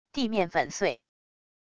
地面粉碎wav音频